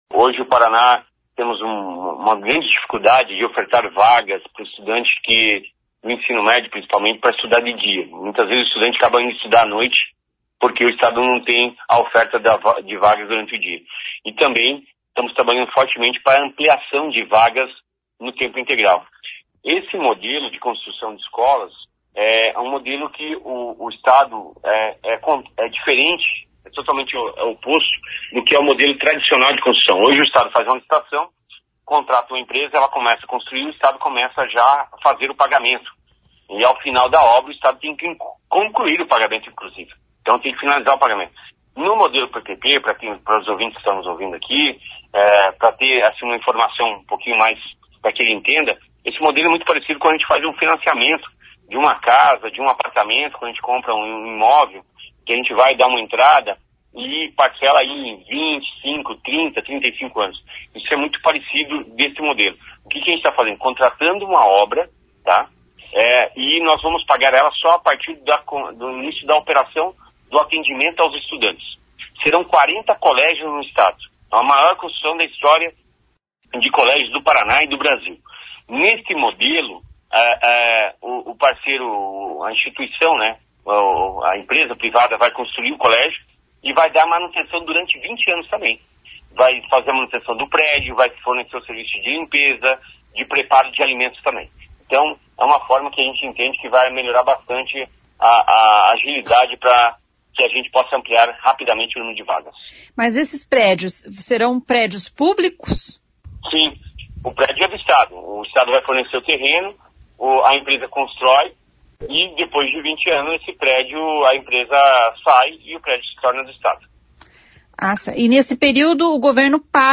Ouça o que diz o secretário de Educação, Roni Miranda.